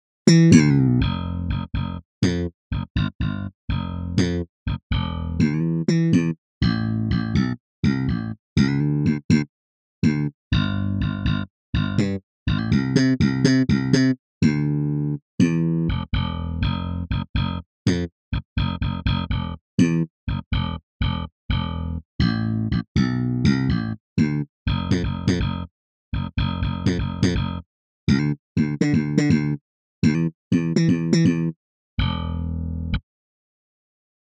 Slap Comp